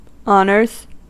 Ääntäminen
Vaihtoehtoiset kirjoitusmuodot honours Ääntäminen US Tuntematon aksentti: IPA : /ˈɑ.nɚz/ Haettu sana löytyi näillä lähdekielillä: englanti Honors on sanan honor monikko.